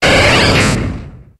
Grito de Flareon.ogg
Grito_de_Flareon.ogg